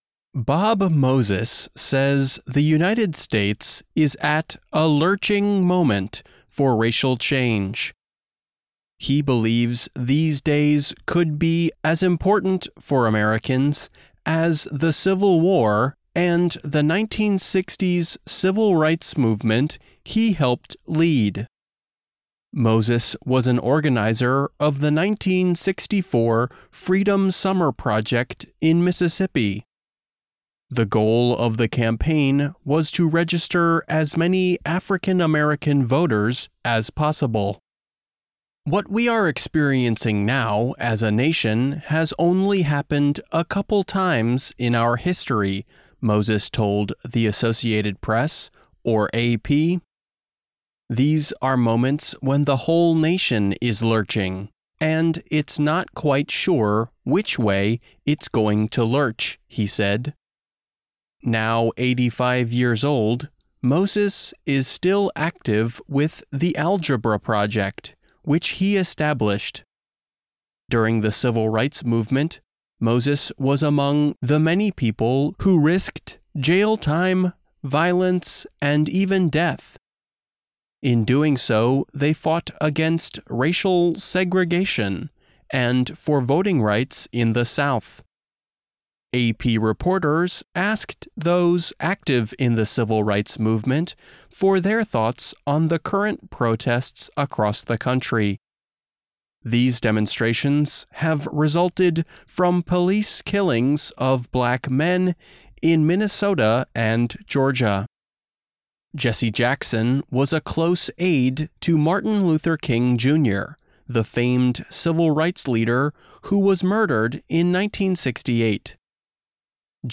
慢速英语:60年代民权领袖对即将到来的变革充满希望